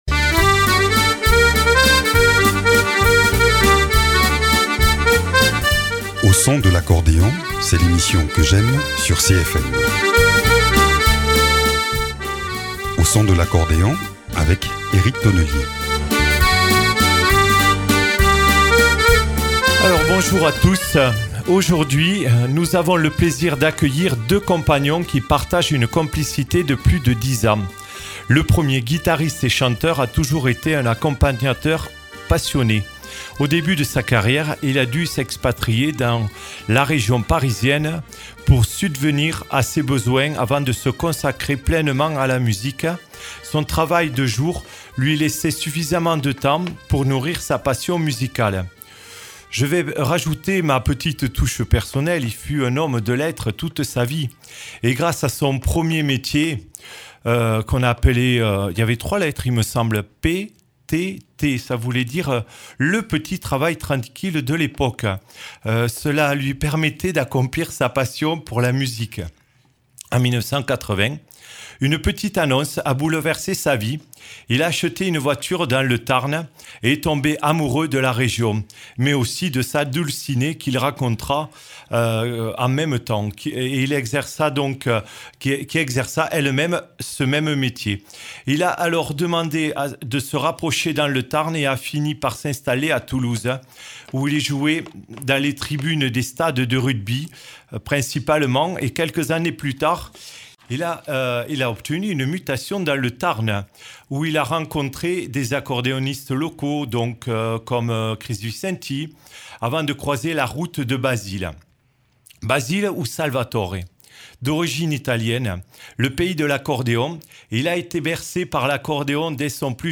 En studio pour nous parler de ce duo formé depuis une dizaine d’années et avec eux le voyage musical est garanti.